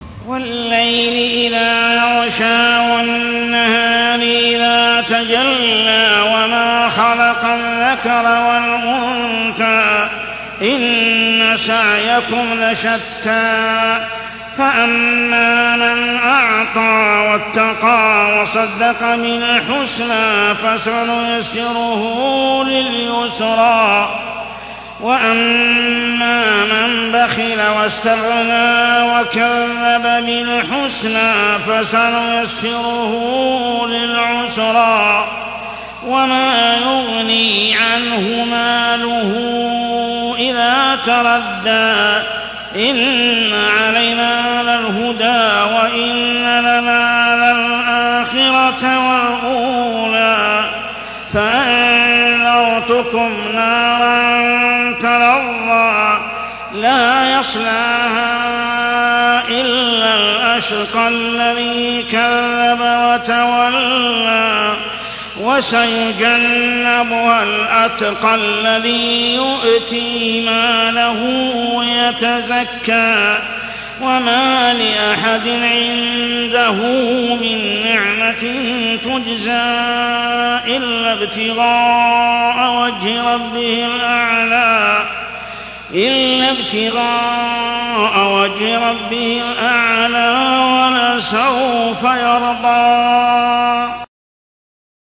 عشائيات شهر رمضان 1424هـ سورة الليل كاملة | Isha prayer Surah Al-Layl > 1424 🕋 > الفروض - تلاوات الحرمين